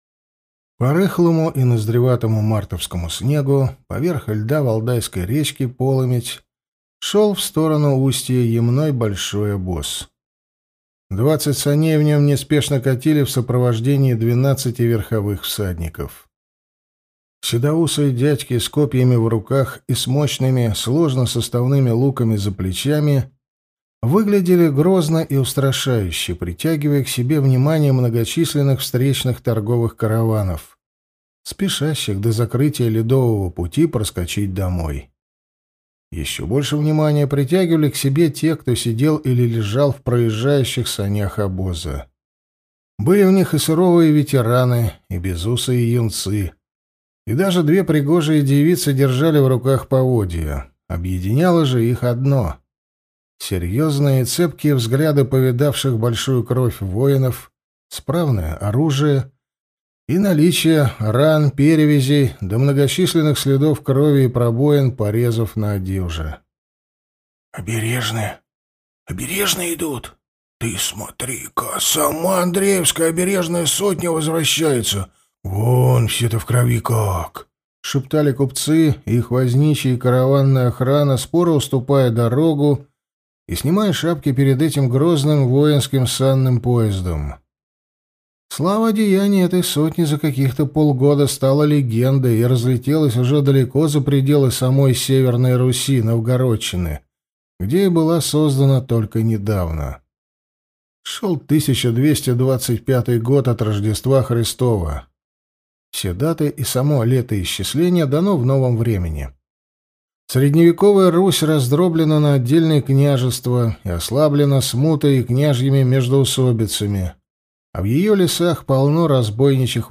Аудиокнига Сотник из будущего. Южный рубеж | Библиотека аудиокниг